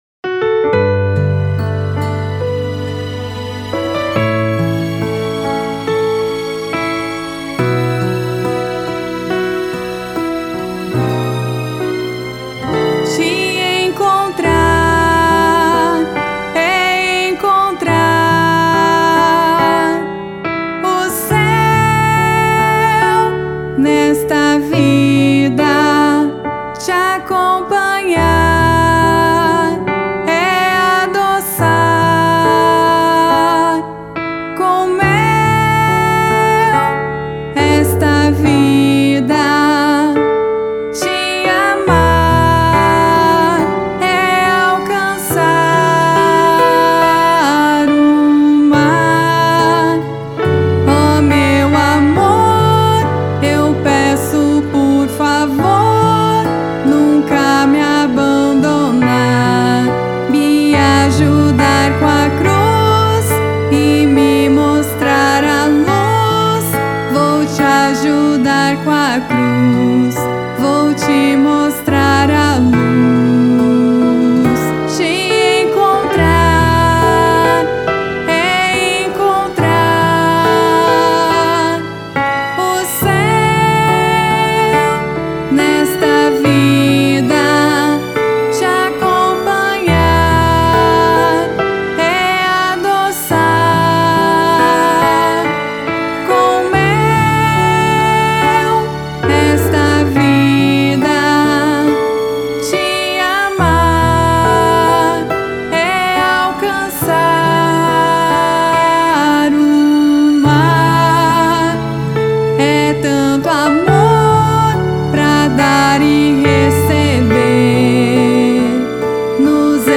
• Violinista